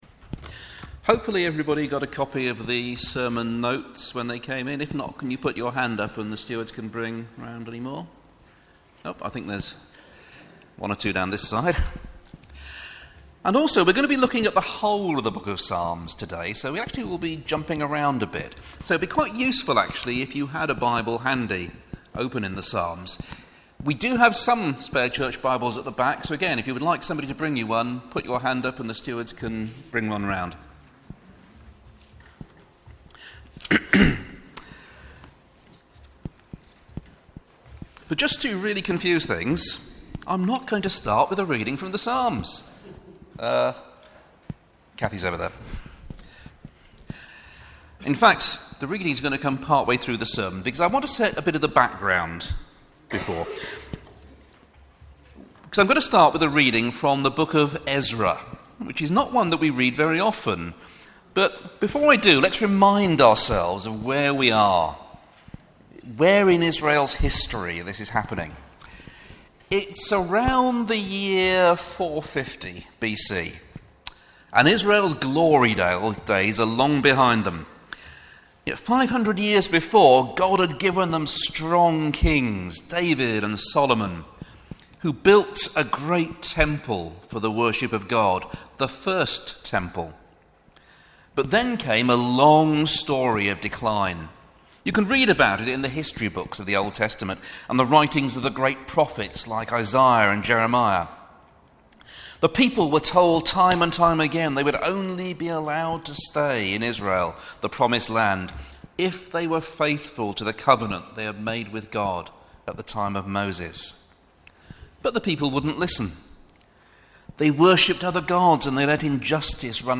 Reading: Psalm 136 Preacher